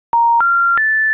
SIT Tone Download